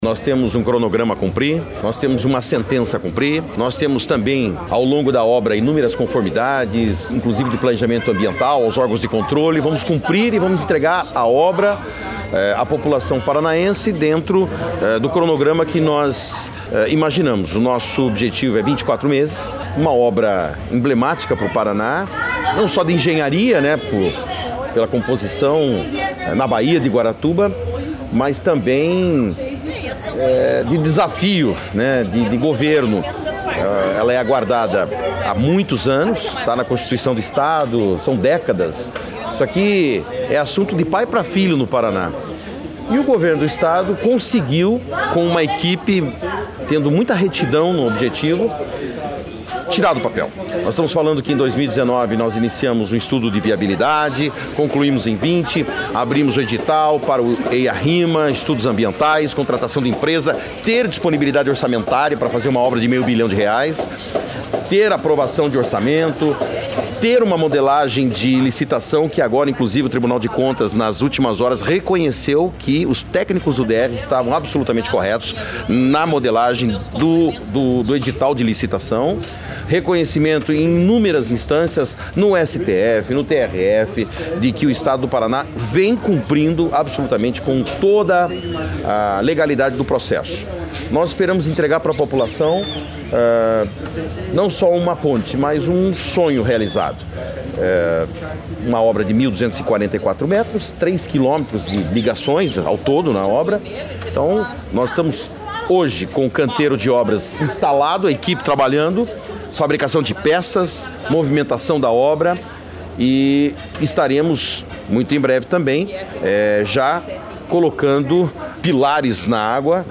Sonora do secretário de Infraestrutura e Logística, Sandro Alex, sobre o início da obra da Ponte de Guaratuba